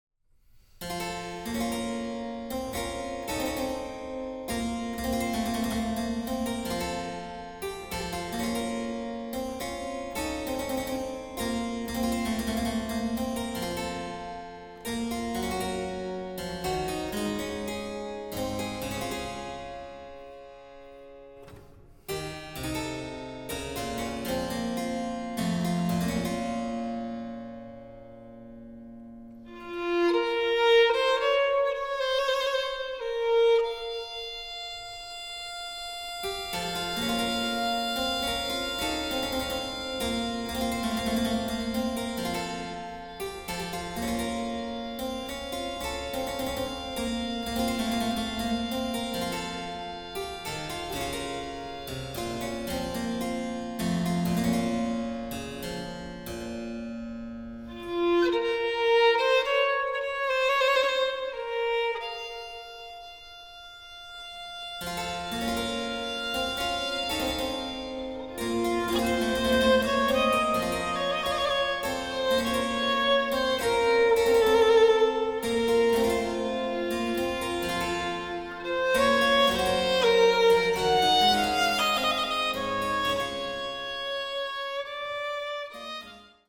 Baroque violin circa 1750, perhaps from Innsbruck.
WGBH Studios, Boston.
by turns sprightly or melancholy.
But “intimate” is a good word too.